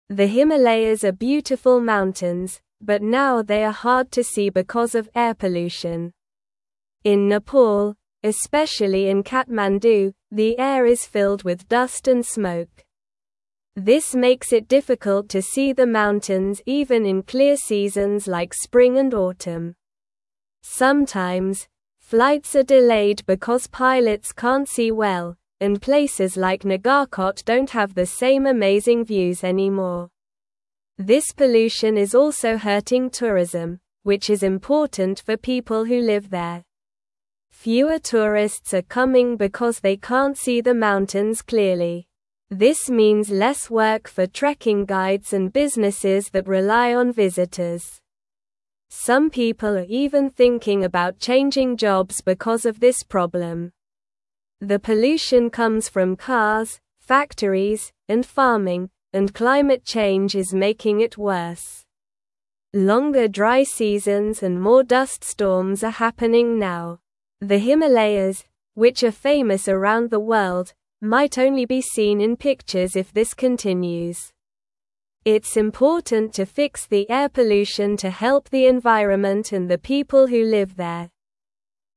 Slow
English-Newsroom-Lower-Intermediate-SLOW-Reading-Dirty-Air-Hides-Beautiful-Himalayas-from-Everyone.mp3